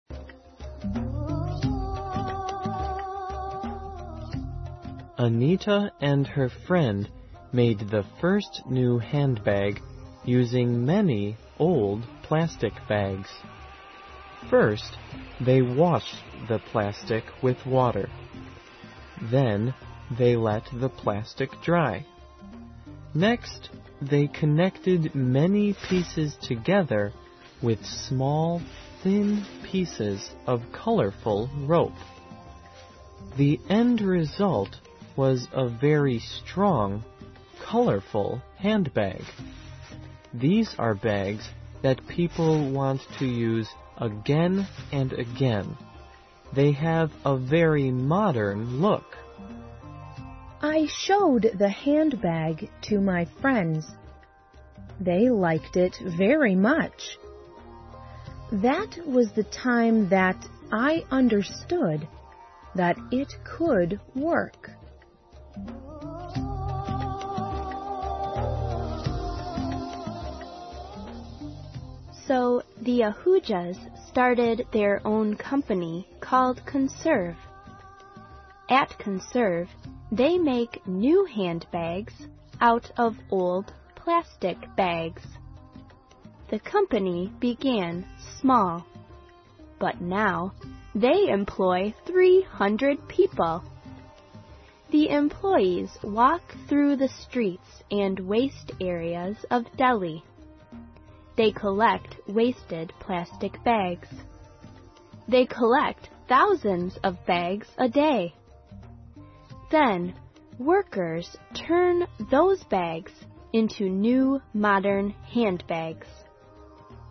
环球慢速英语 第103期:塑料袋废物(4)